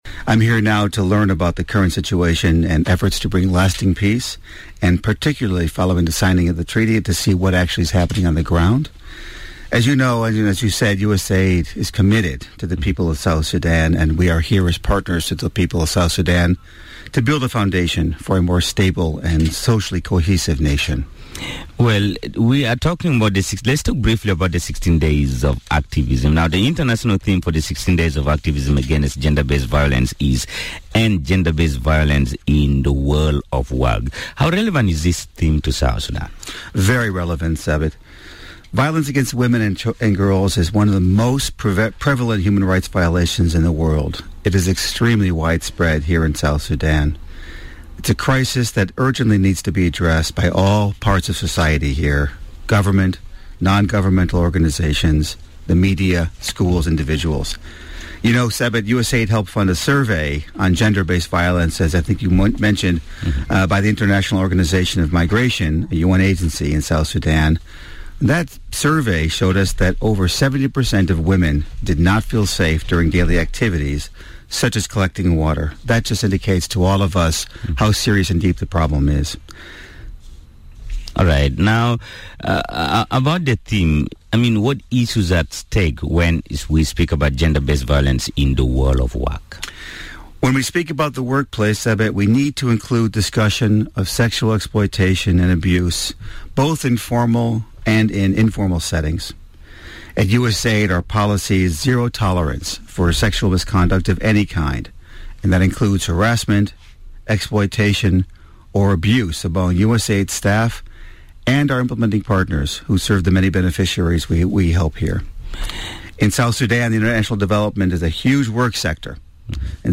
Speaking on the Miraya Breakfast show, Mr. Kamin said his government is working to support the people of South Sudan in seeking peace and ending all forms of violence and abuse against women and girls.